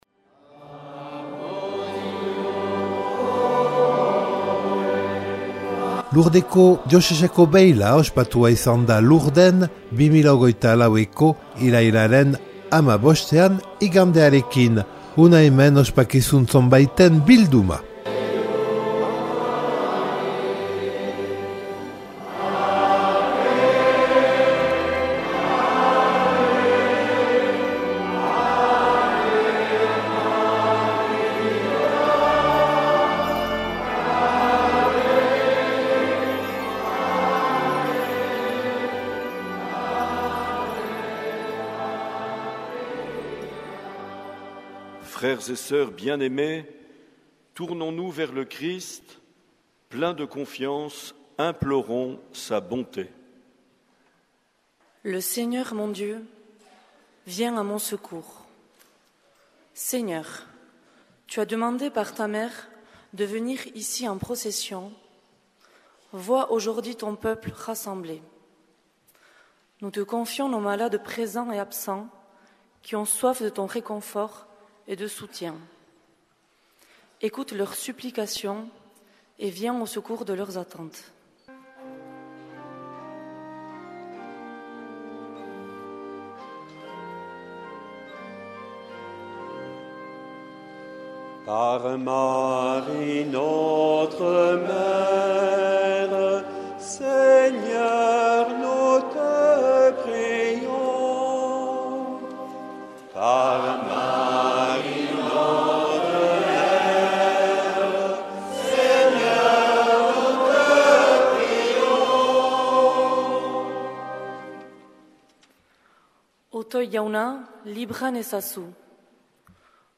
Elkarrizketak eta erreportaiak